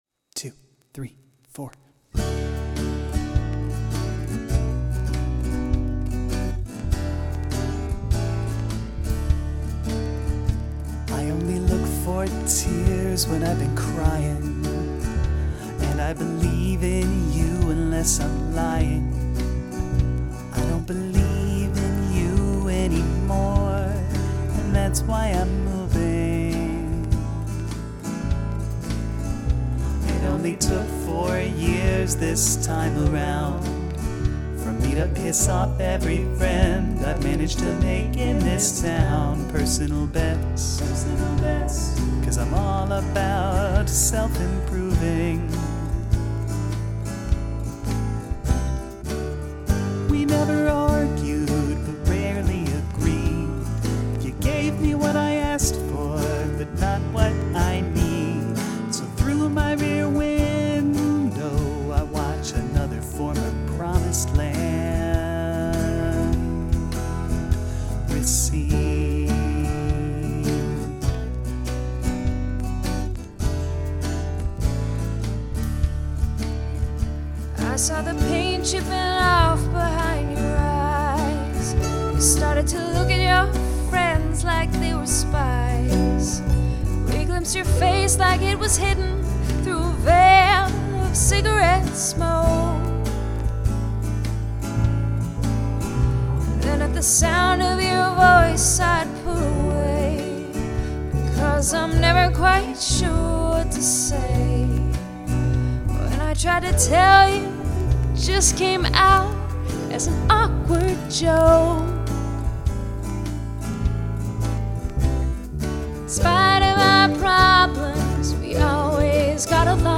The melody fits really well.